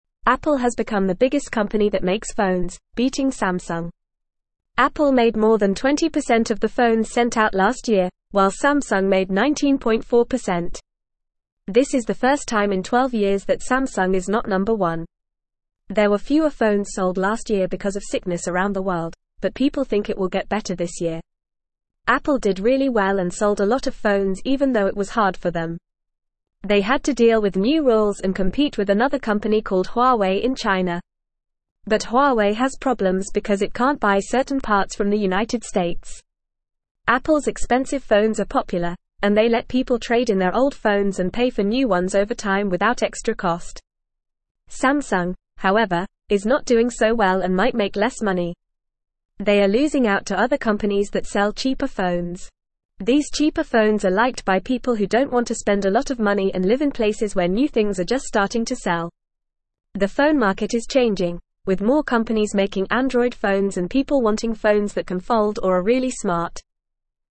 Fast
English-Newsroom-Lower-Intermediate-FAST-Reading-Apple-Makes-the-Most-Phones-Samsung-Not-Doing-Well.mp3